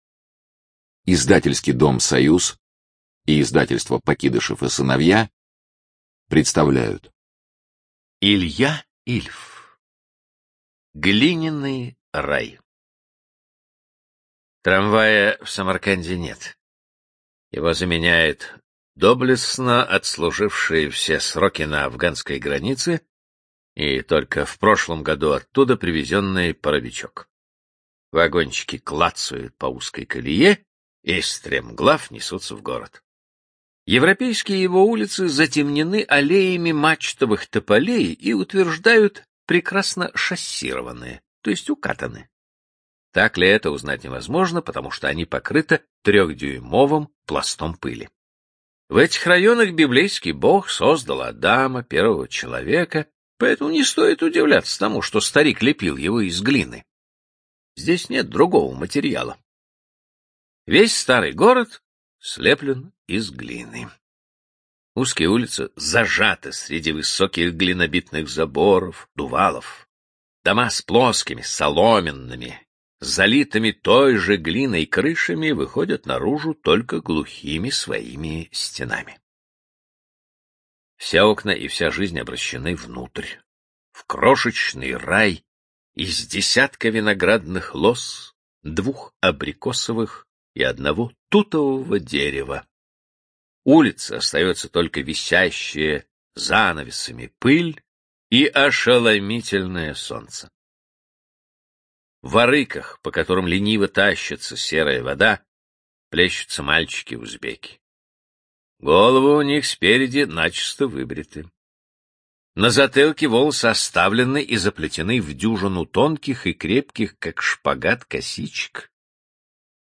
Студия звукозаписиСоюз